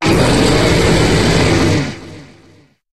Cri de Zekrom dans Pokémon HOME.